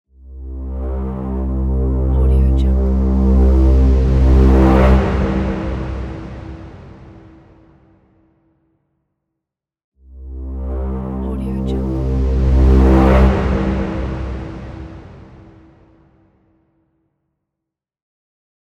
دانلود افکت صوتی Digital Swell